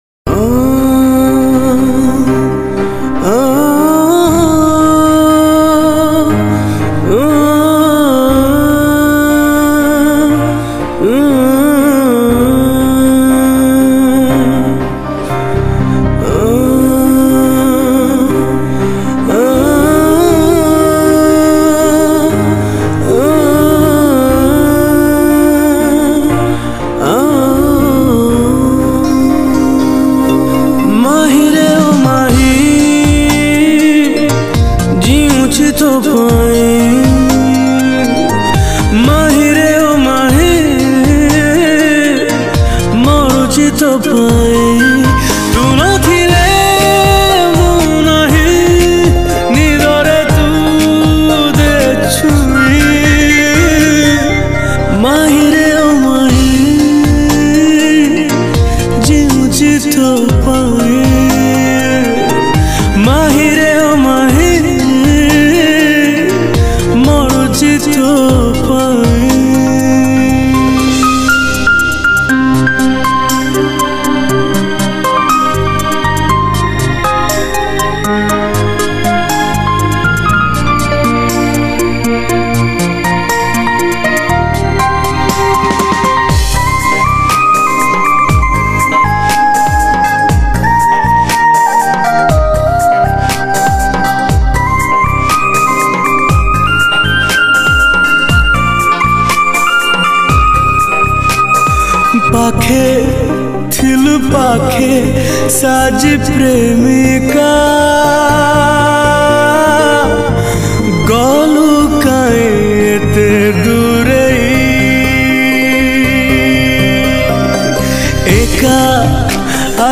Odia Romantic Song